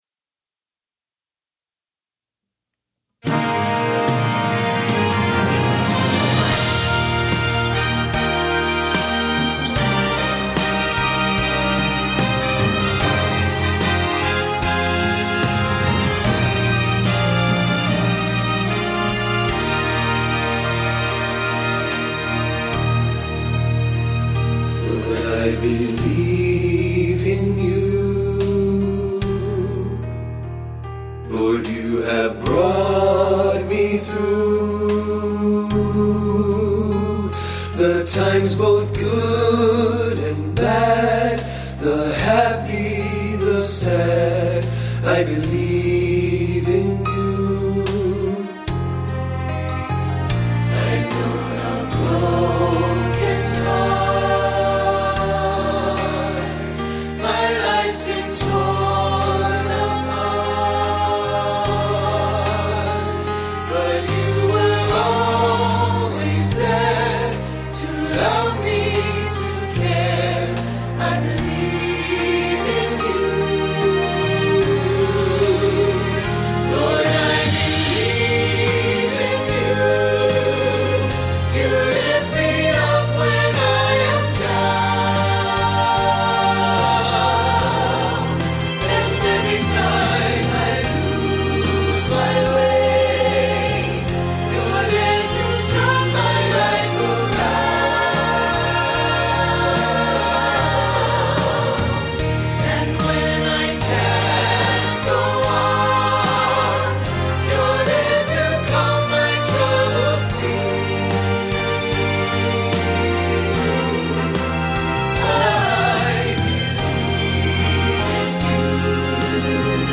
accessible for choirs of any size